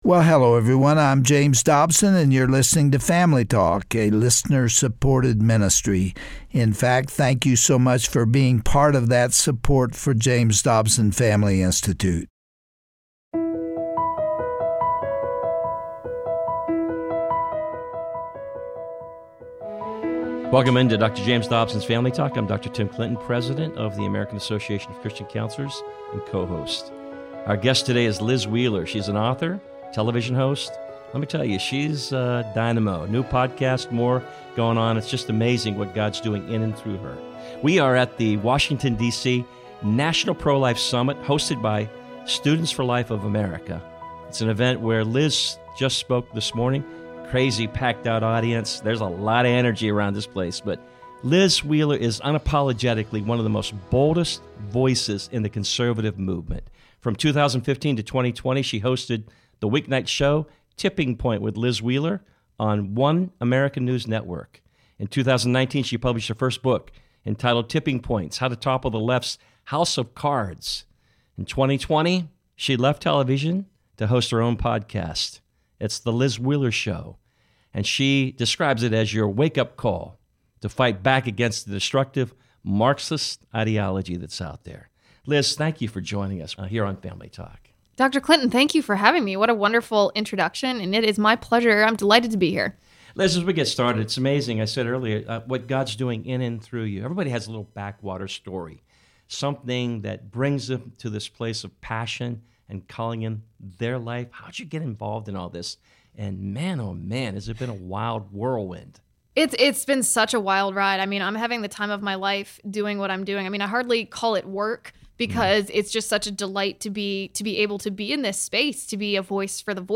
On today's edition of Family Talk, political commentator, news personality, and podcast host, Liz Wheeler, firmly declares that what we are facing today in our culture is a blatant attempt to delegitimize our nation. Citing the 1619 Project, Critical Race Theory and the transgender movement, Wheeler believes there is an outright agenda to destroy institutions that have defined our nation since its origin.